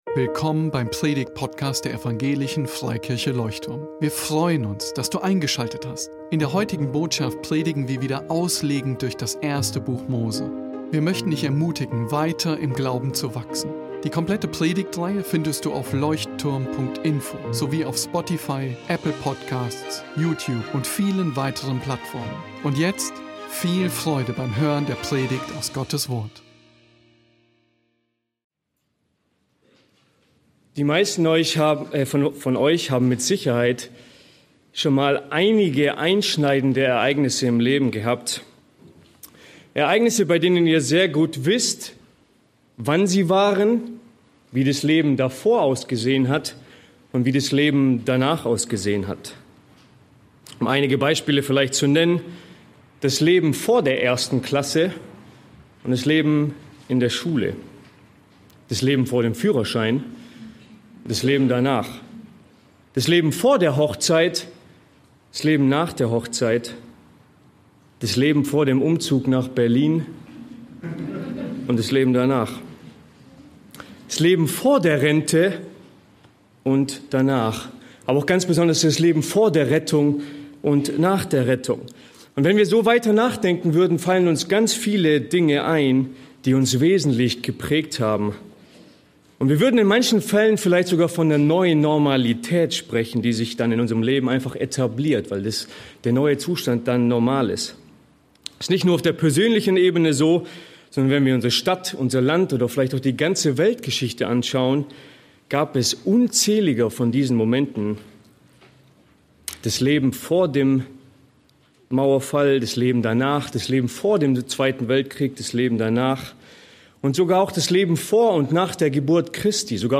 Leuchtturm Predigtpodcast